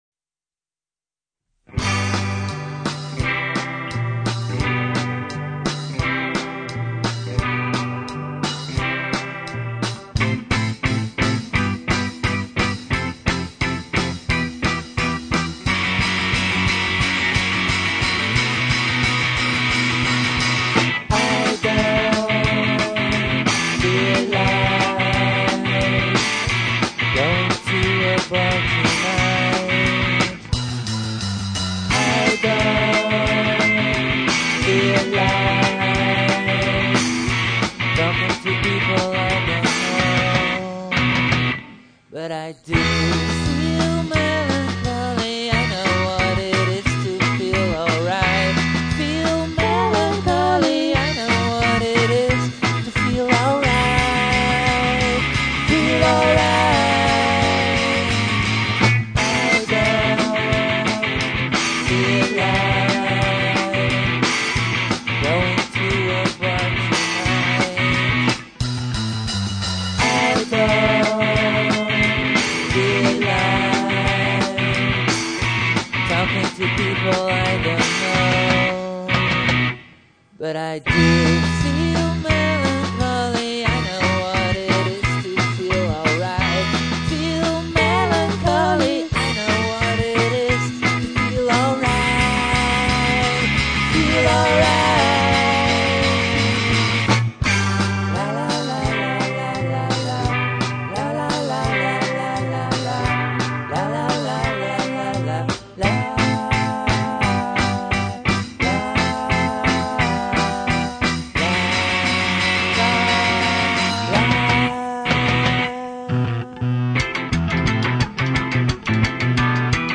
recorded at CMA (Amsterdam)
discovers the overdrive pedal